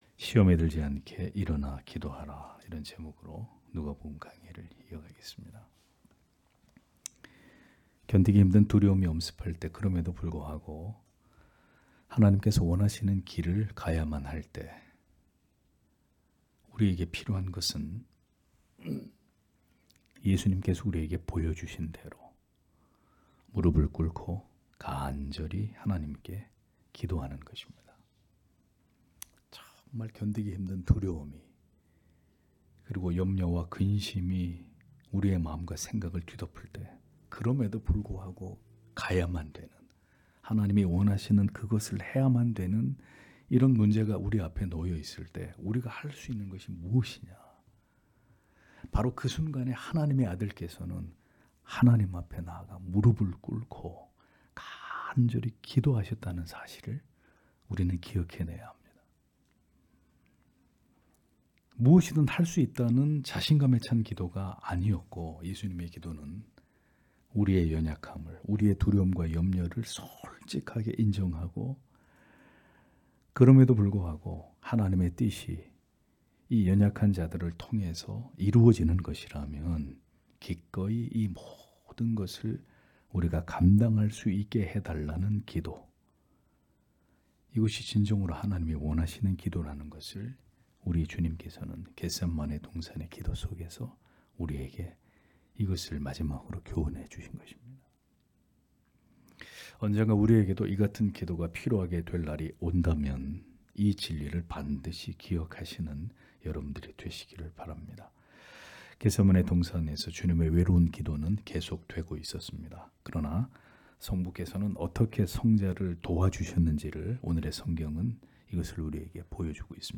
금요기도회 - [누가복음 강해 170] '시험에 들지 않게 일어나 기도하라' (눅 22장 43- 46절)